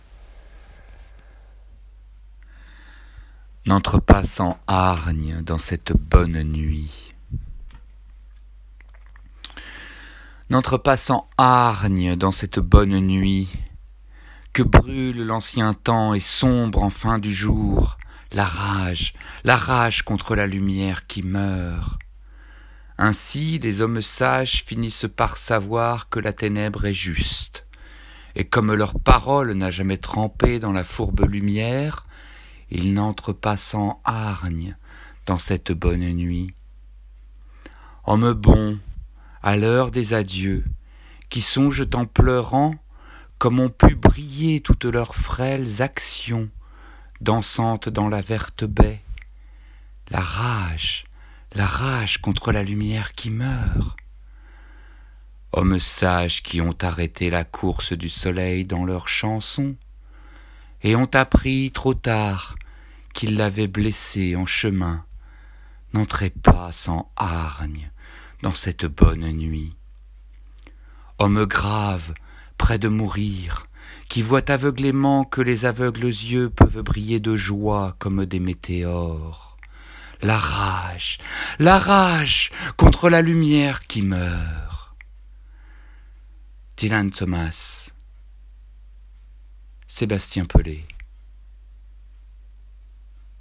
AUDIO READING / Do not go gentle into that good night / N’entre pas sans hargne dans cette bonne nuit